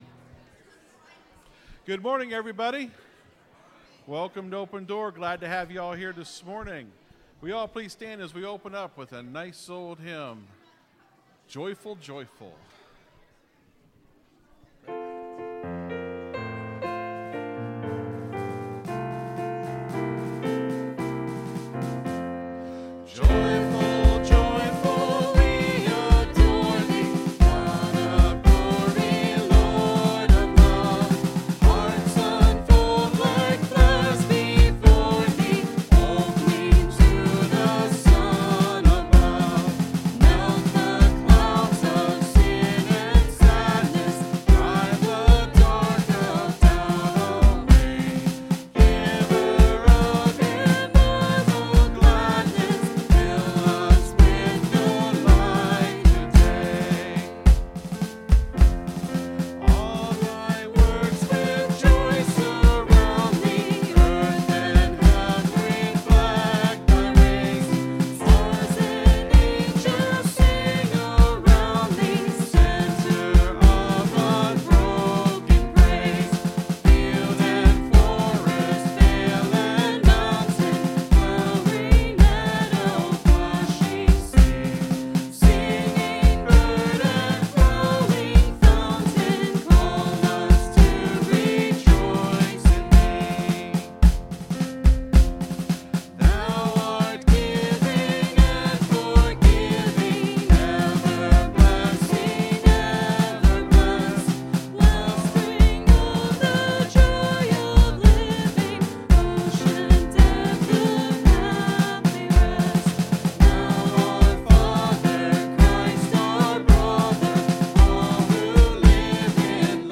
(Sermon starts at 26:15 in the recording).